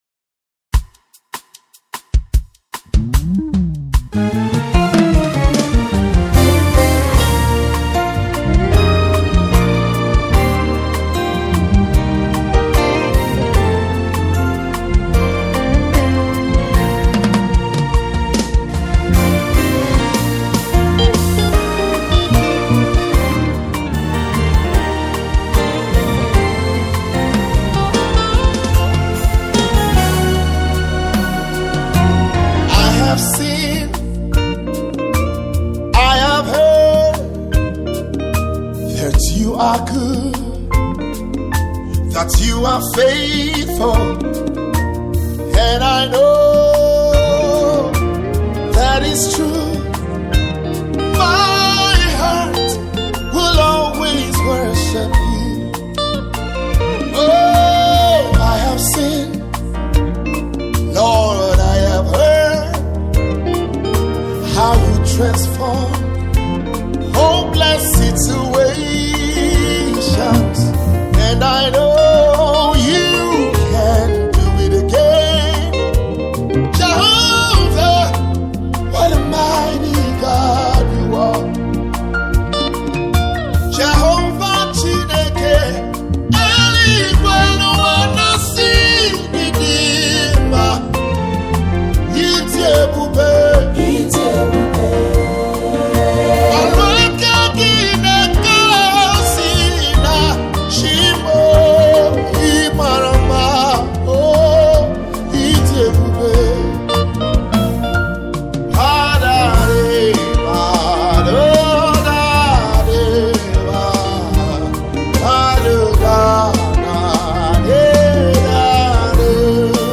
The worship single is Afrocentric.